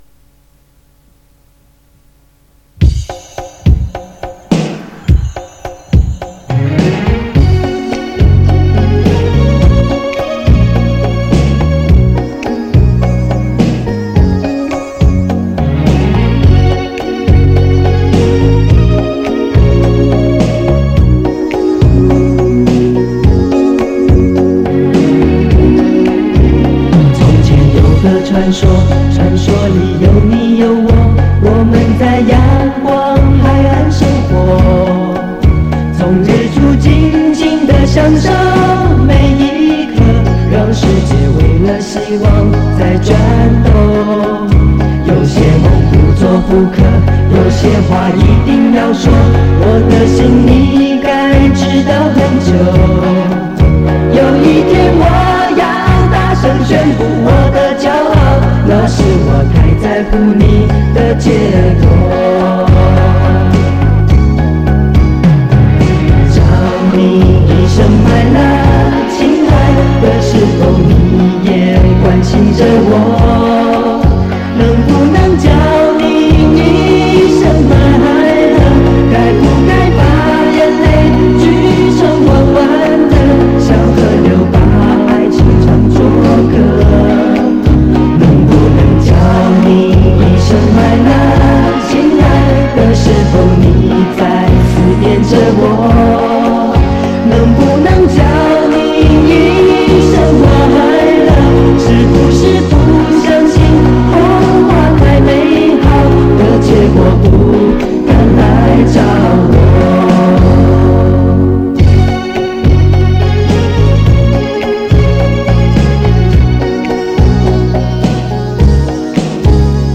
磁带数字化：2022-09-04